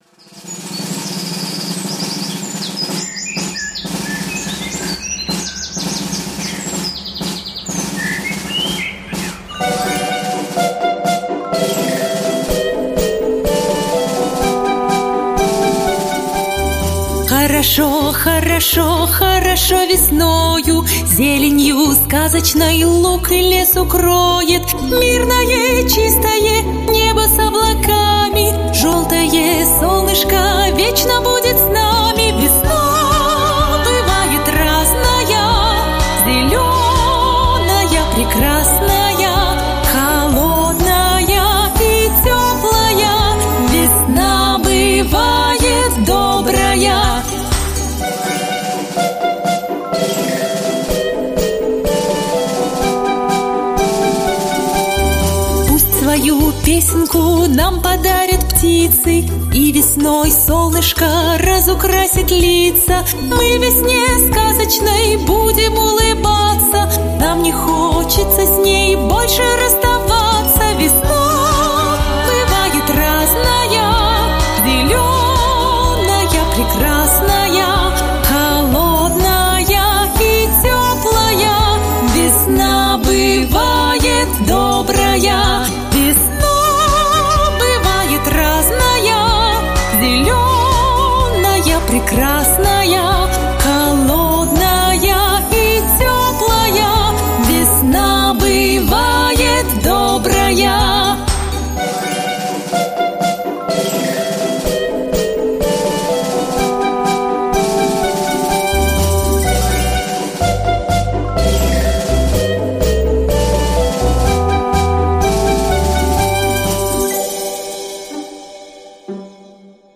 Песенки про весну